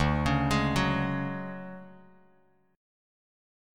DM#11 chord